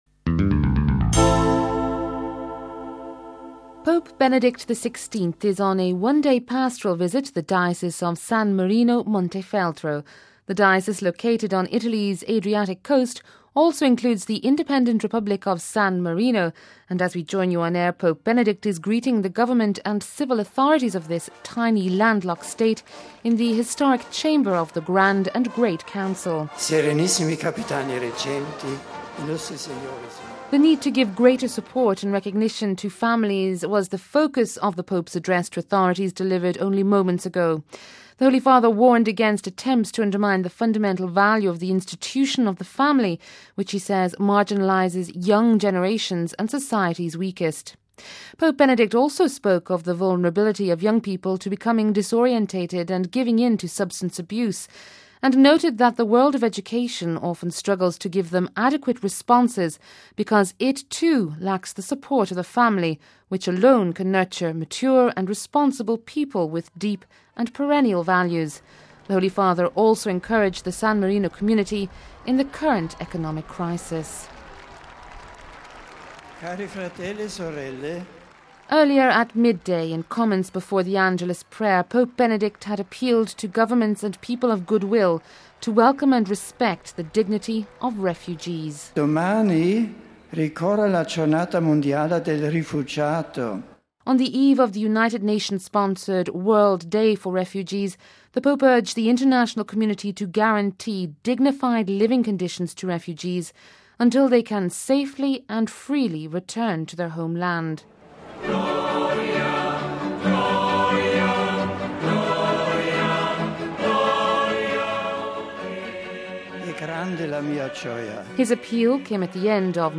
Pope Benedict XVI appealed to civil authorities and “all people of goodwill to guarantee welcome and dignified living conditions to refugees” this Sunday on the eve of the UN’s World Day for Refugees. The Holy Father launched his appeal from Serravalle Stadium in the tiny landlocked Republic of San Marino (in the centre of the Italian peninsula), on a one day pastoral visit to the diocese of San Marino-Montefeltro.